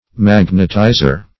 Magnetizer \Mag"net*i`zer\, n. One who, or that which, imparts magnetism.